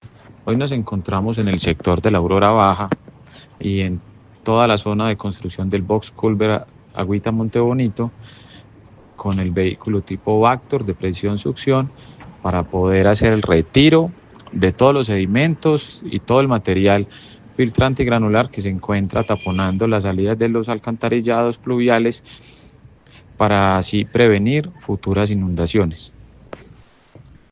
Felipe-Mejia-Secretario-Obras-Publicas-.mp3